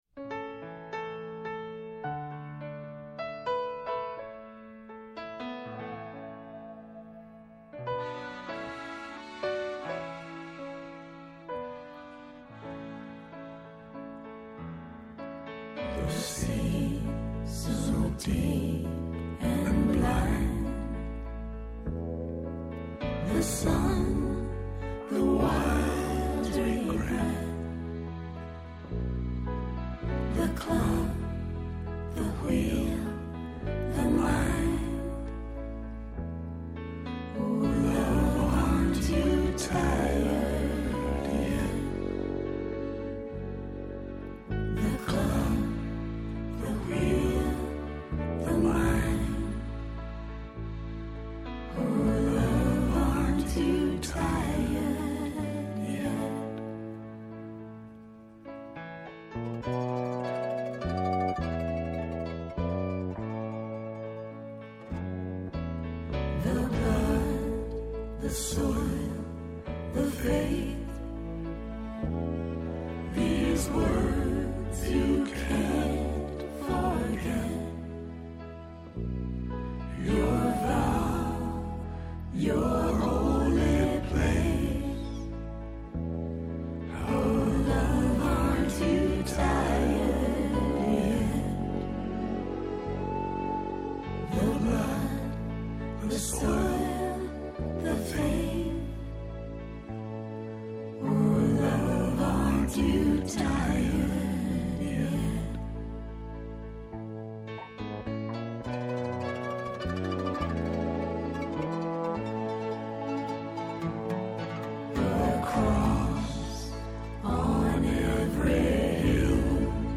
Στην σημερινή εκπομπή καλεσμένοι :
-Η Σώτη Τριανταφύλλου, Συγγραφέας